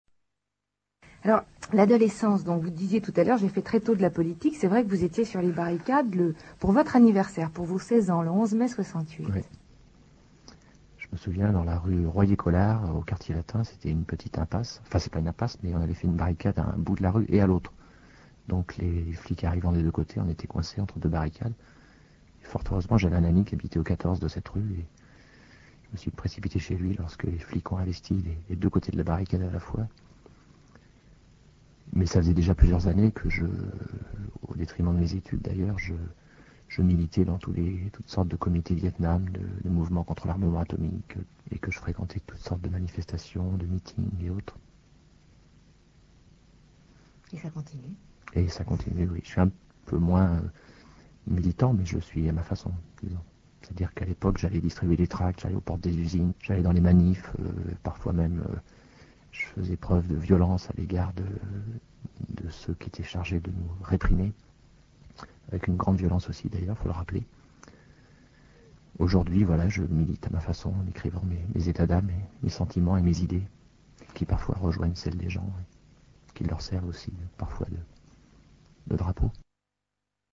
Interview de Renaud à RTL le 9 octobre 1989